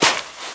STEPS Sand, Walk 07.wav